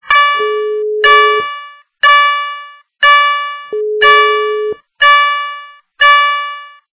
» Звуки » звуки для СМС » на СМС - Автомобильный Передзвон
При прослушивании на СМС - Автомобильный Передзвон качество понижено и присутствуют гудки.
Звук на СМС - Автомобильный Передзвон